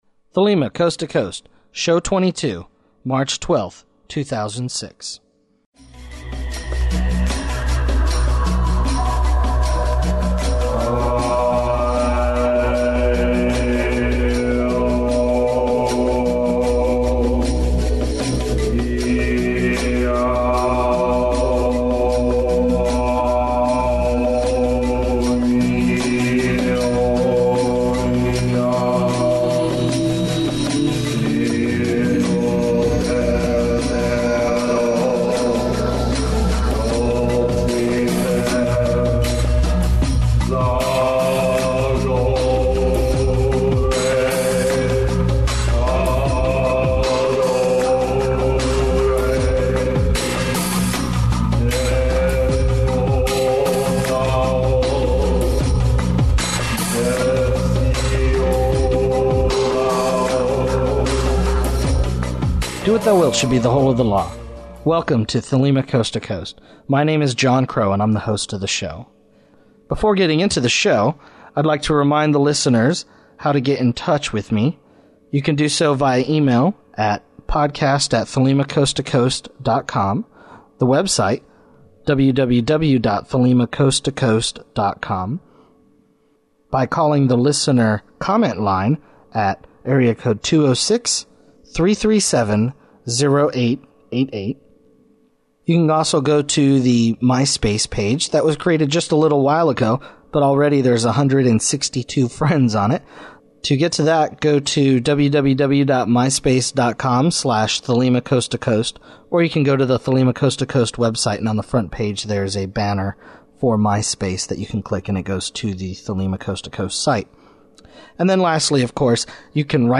Opening music
about the History & Prehistory of the OTO given at William Blake Oasis, OTO , November 2005
Closing music